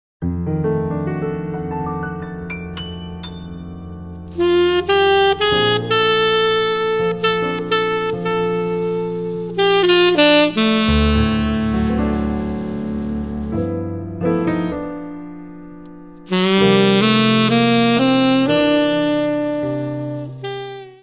Descrição Física:  1 disco (CD) (53 min.) : stereo; 12 cm
Área:  Jazz / Blues